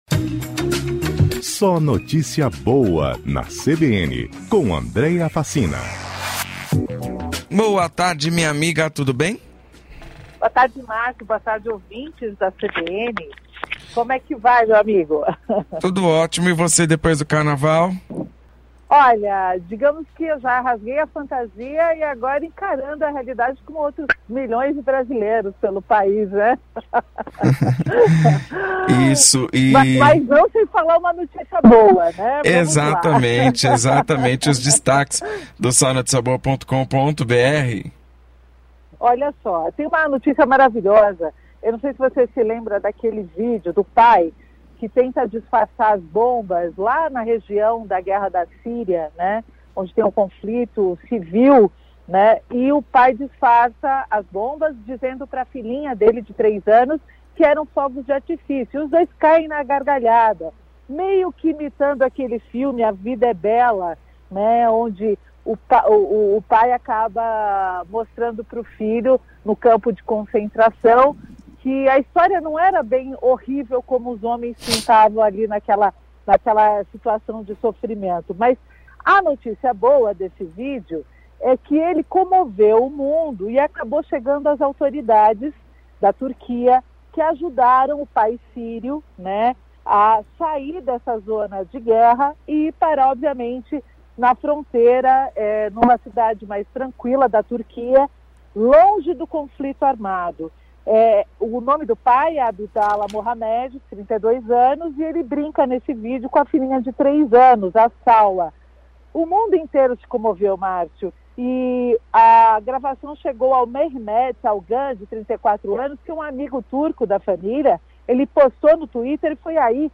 O quadro SNB na CBN vai ao ar de segunda a sexta às 16:55 na rádio CBN Grandes Lagos.